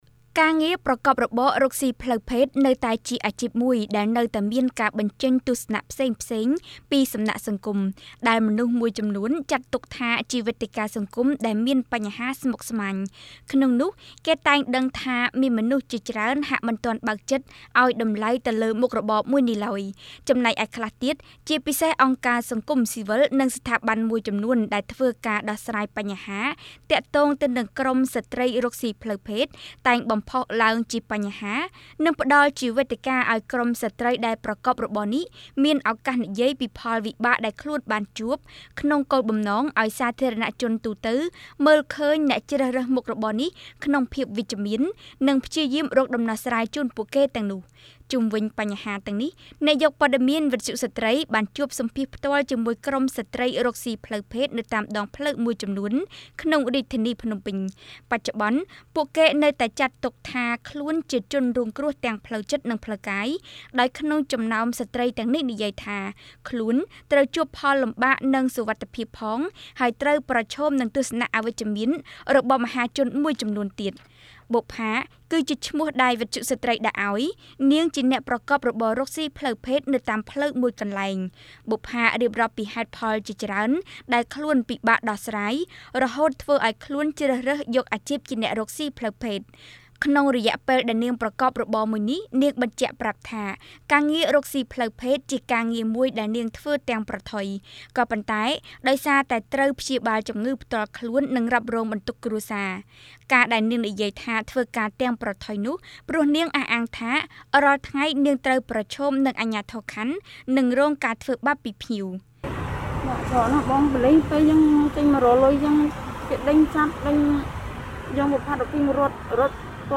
បទយកការណ៍៖ អ្នកប្រកបរបរផ្លូវភេទទូចឱ្យសង្គមរំដោះពួកគេចេញពីអាជីពនេះ ជាជាងខ្វះការយកចិត្តទុកដាក់ព្រោះផ្នត់គំនិតសង្គម
ជុំវិញបញ្ហាទាំងនេះ អ្នកយកព័ត៌មានវិទ្យុស្រ្តីបានជួបសម្ភាសន៍ផ្ទាល់ជាមួយក្រុមស្រ្តីរកស៊ីផ្លូវភេទនៅតាមដងផ្លូវមួយចំនួនក្នុងរាជធានីភ្នំពេញ។ បច្ចុប្បន្នពួកគេនៅតែចាត់ទុកថាខ្លួនជាជនរងគ្រោះទាំងផ្លូវចិត្ត និងផ្លូវកាយ ដោយក្នុងចំណោមស្រ្តីទាំងនេះនិយាយថាខ្លួនត្រូវជួបផលលំបាកនិងសុវត្ថិភាពផង ហើយត្រូវប្រឈមនឹងទស្សនៈអវិជ្ជមានរបស់មហាជនមួយចំនួនទៀត។